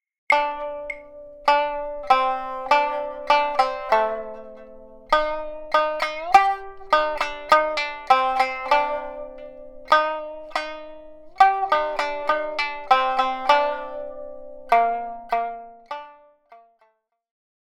Classic folk song for shamisen.
• niagari tuning (C-G-C)